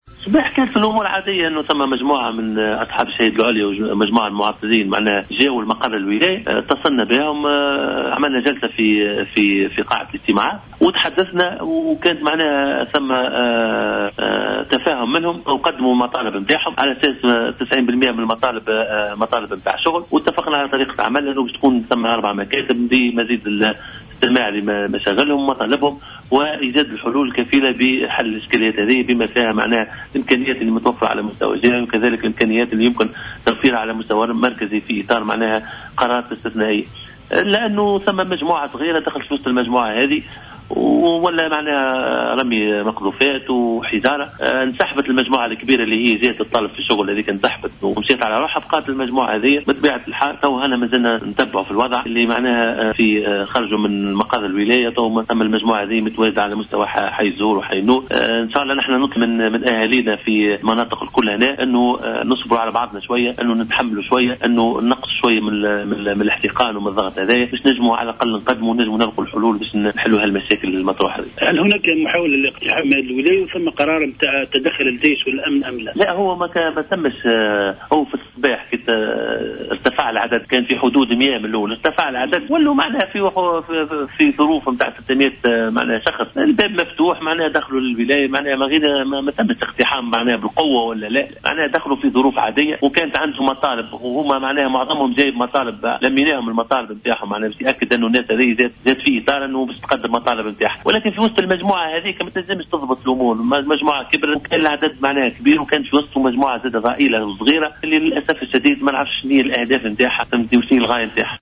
Le gouverneur de Kasserine, Chedli Bouallègue, a indiqué ce mardi 19 janvier 2016, dans une déclaration à Jawhara Fm, que des individus ont infiltré le groupe de demandeurs d’emploi qui se sont réunis devant le siège du gouvernorat et ont provoqué la violente manifestation qui a secoué la ville aujourd’hui.